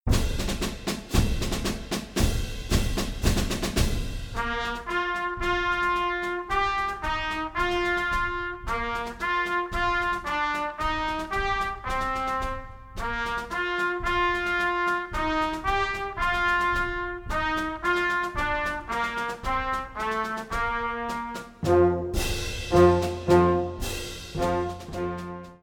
Kategorie Blasorchester/HaFaBra
Unterkategorie Konzertmusik
Schwierigkeitsgrad 1